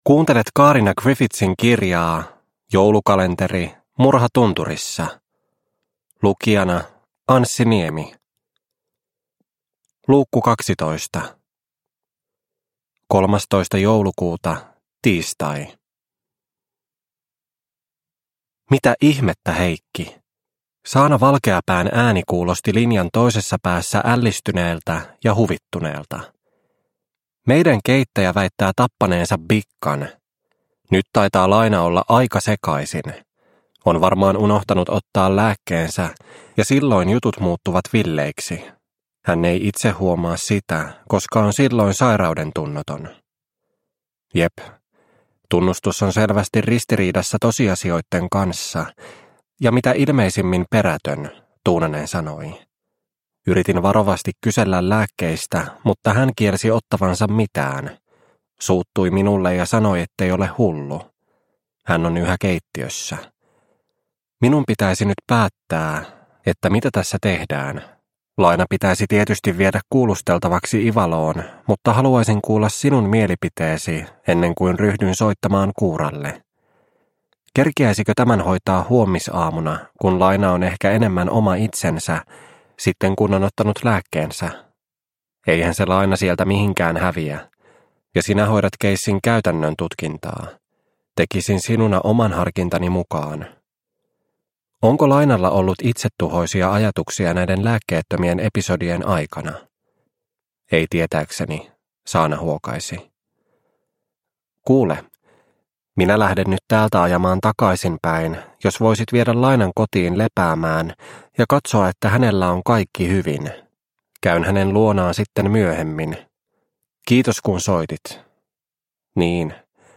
Murha tunturissa - Osa 12 – Ljudbok – Laddas ner